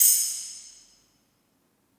Metro Single Open Hat.wav